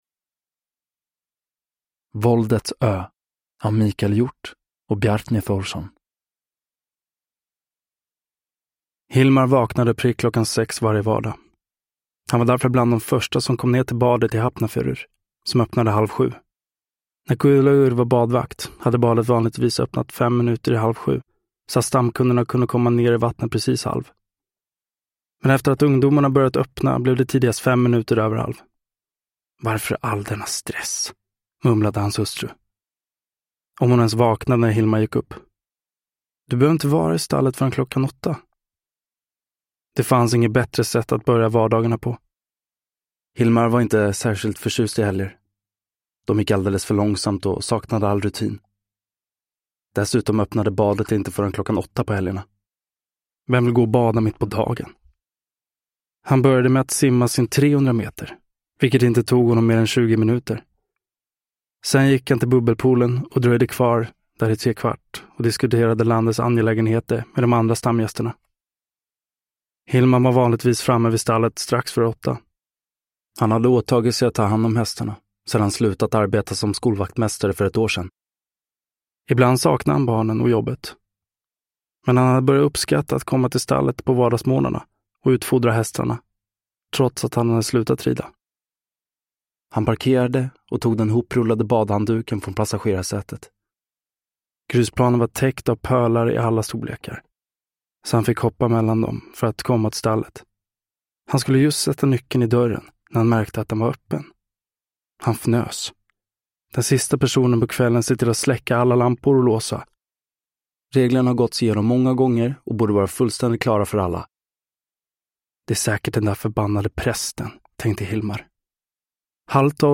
Våldets ö (ljudbok) av Michael Hjorth